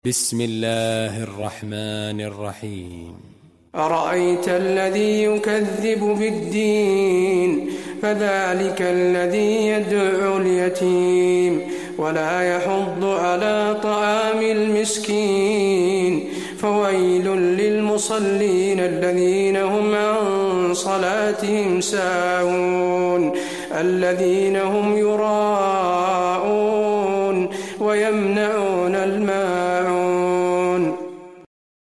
المكان: المسجد النبوي الماعون